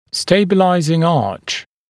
[‘steɪbəlaɪzɪŋ ɑːʧ][‘стэйбэлайзин а:ч]стабилизирующая дуга